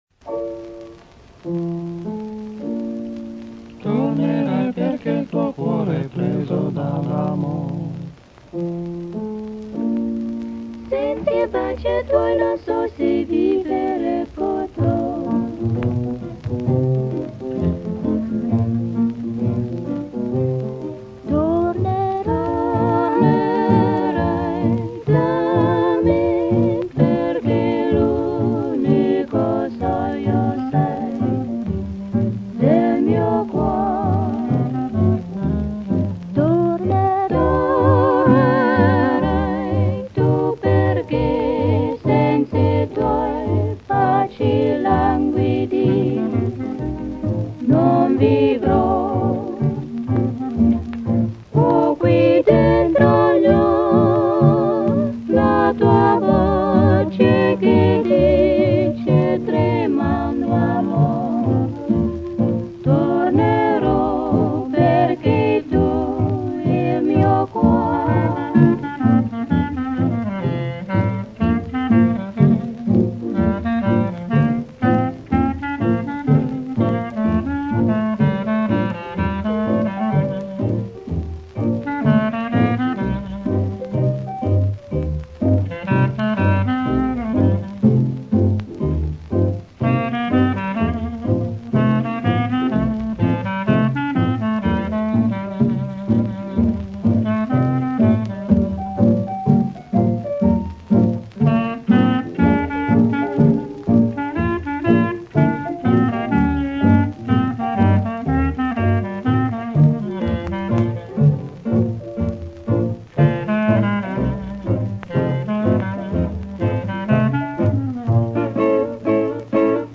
Da supporto 78 giri